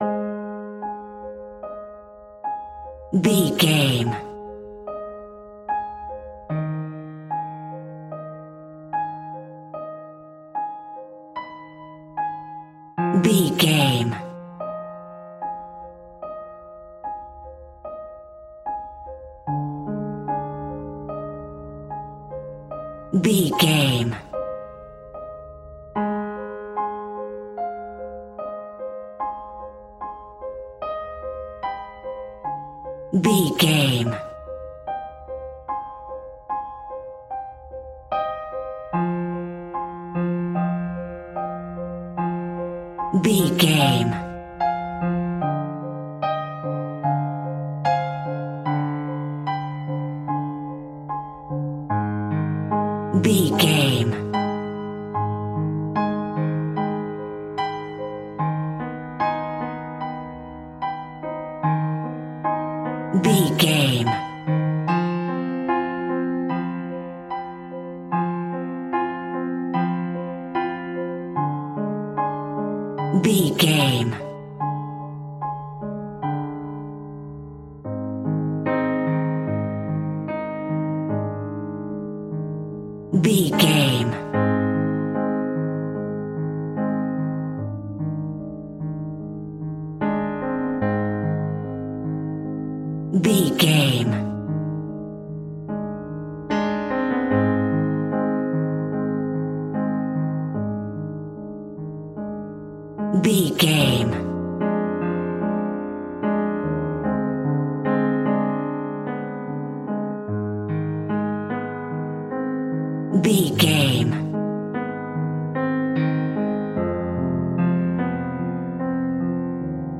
Twisted Piano Music Cue.
In-crescendo
Thriller
Aeolian/Minor
ominous
suspense
eerie
Acoustic Piano